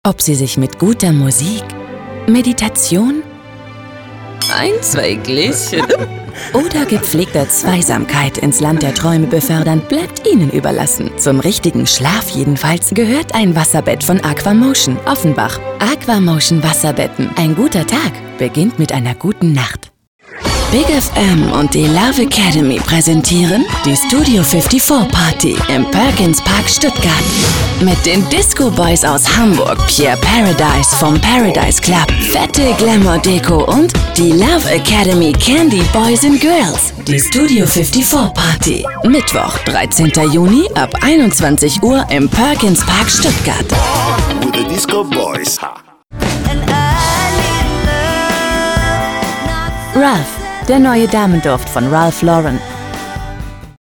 deutsche Profi Sprecherin mit einer vielseitigen Stimme: freundlich warm - szenemässig cool - sinnlich lasziv . Spezialität: Dialekte und Akzente
Sprechprobe: Werbung (Muttersprache):